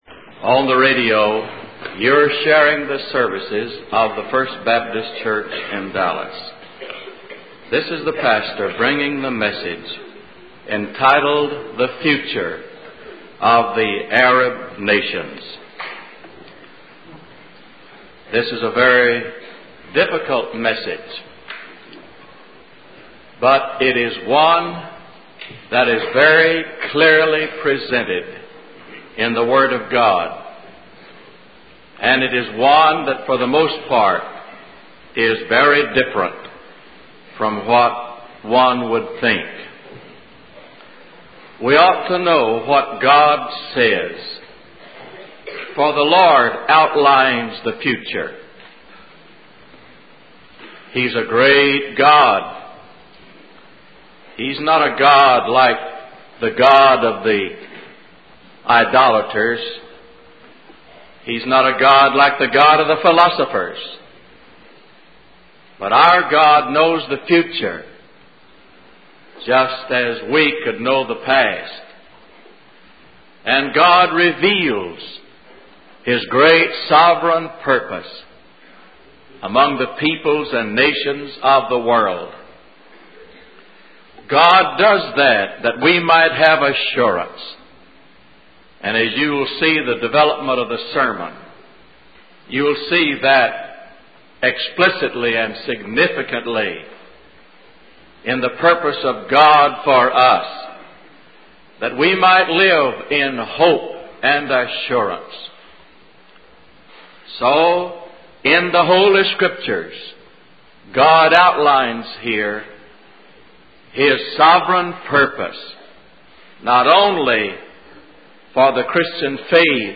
W. A. Criswell Sermon Library | The Future of the Arab Nations